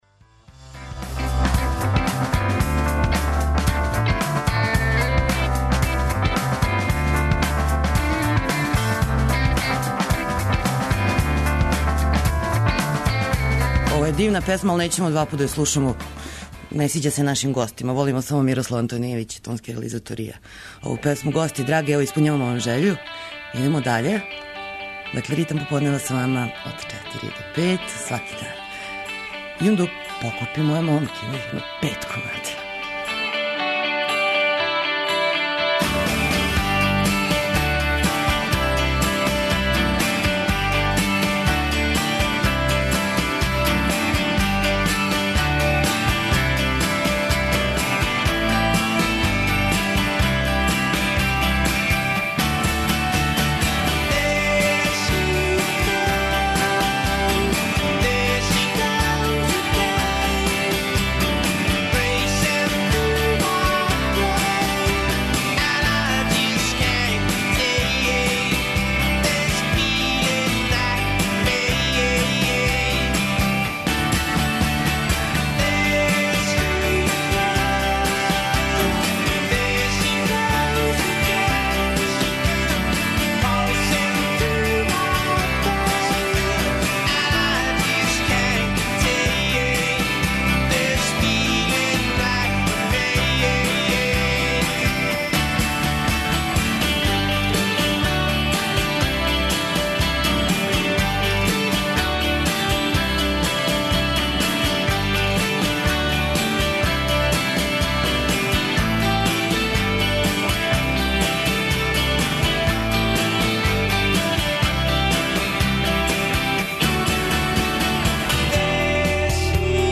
Неколико музичких тема лако је стало у сат програма, а четворо гостију скоро једнако лако у студио Радио Београда 1.